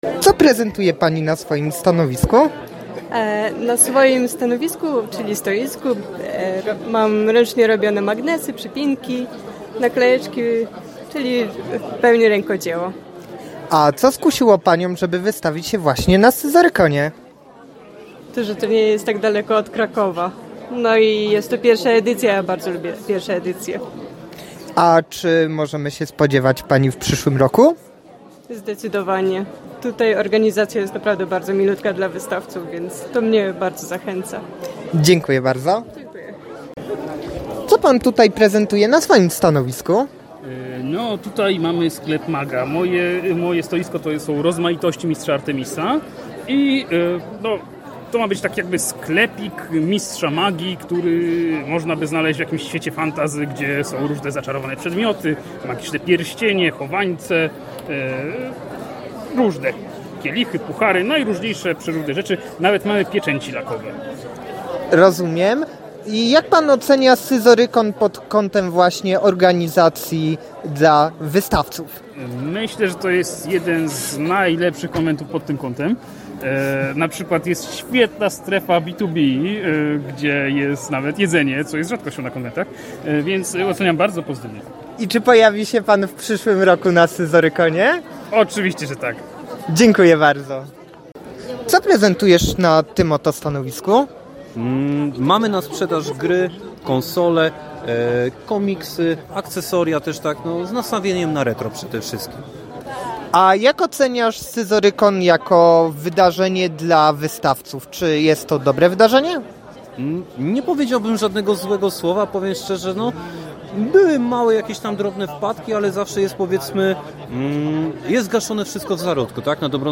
Jeśli były jakieś drobne problemy, wszystko było od razu gaszone w zarodku – mówił jeden z nich.
Organizacja naprawdę przyjazna dla wystawców – dodaje inny.
Scyzorykon-wystawcay.mp3